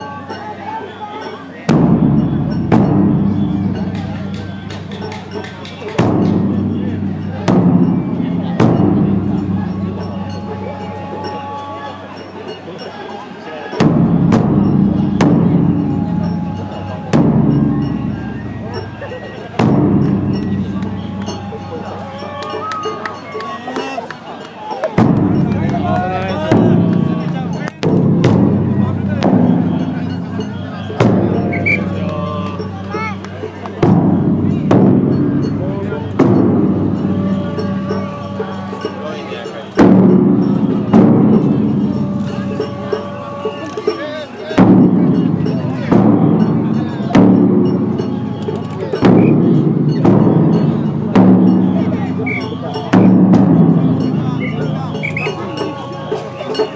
Big drum